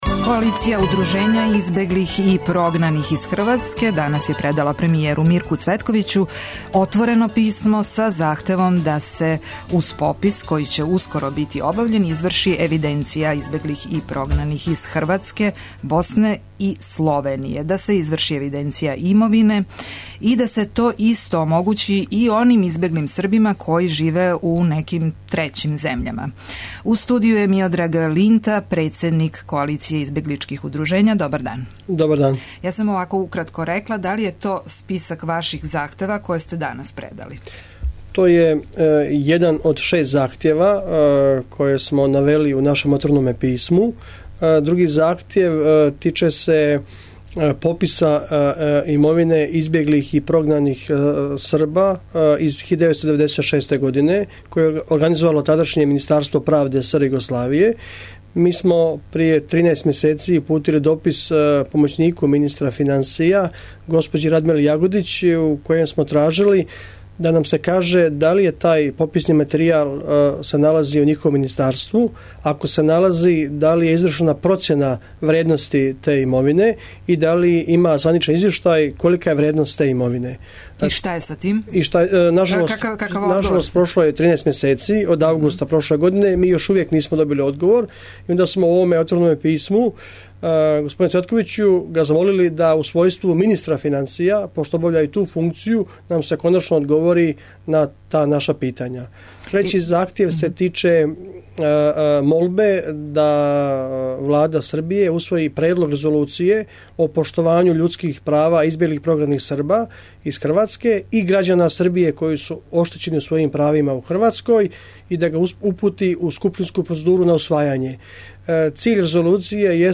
Гост у студију биће Миодраг Линта, председник Колације избегличких удружења. У истој емисији емитоваћемо и интервју са бившим председником Хрватске Стјепаном Месићем који, између осталог, говори о односима две државе и о свом виђењу будућности Косова и Метохије.